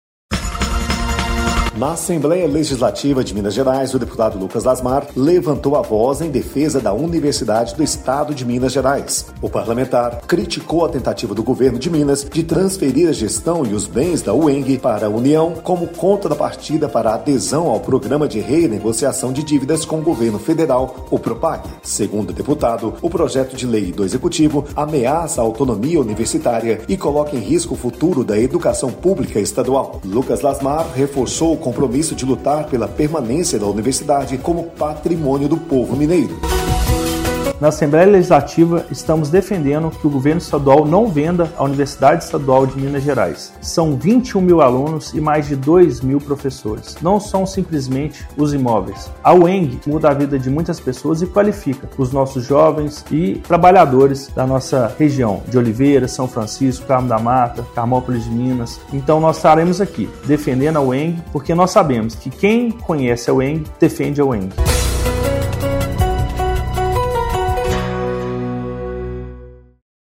Boletim de Rádio